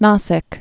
(näsĭk)